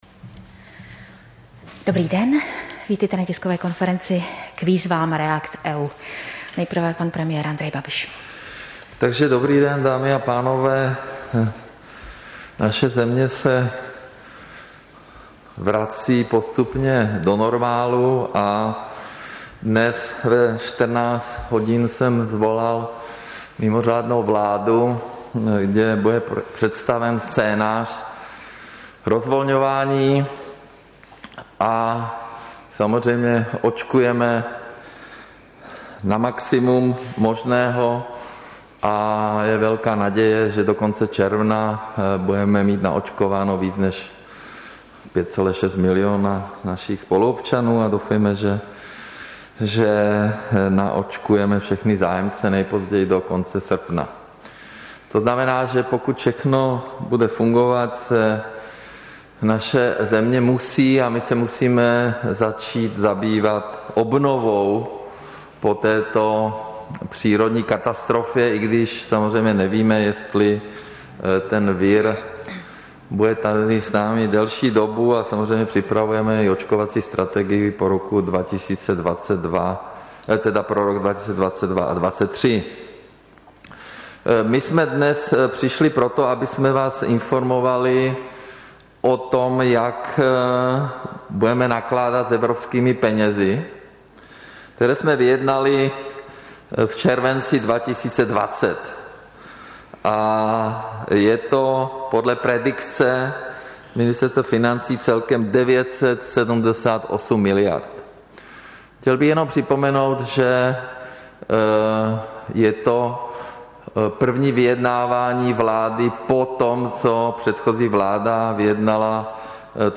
Tisková konference ke spuštění výzvy REACT-EU v oblasti zdravotnictví a IZS, 22. dubna 2021